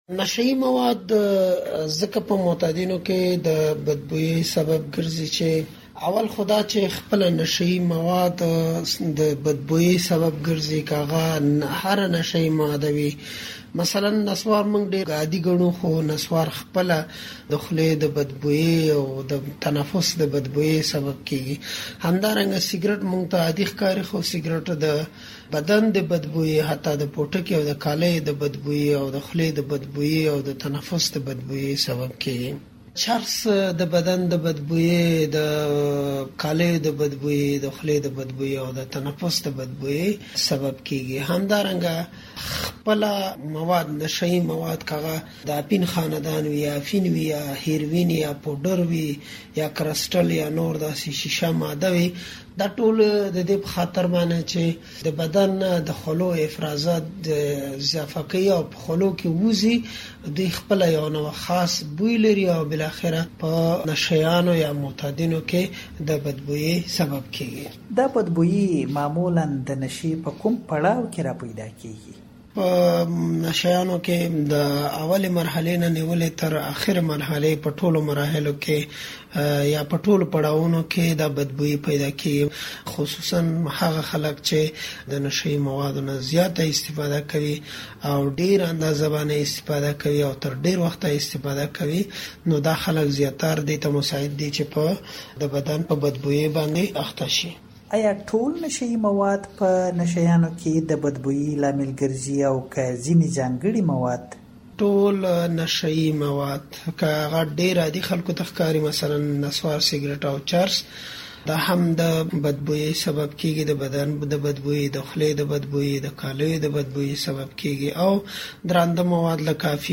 دا مرکه د لاندې غږيز فايل په کېکاږلو اورېدلی شئ: